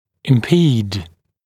[ɪm’piːd][им’пи:д]мешать, препятствовать, быть помехой чему-л., затруднять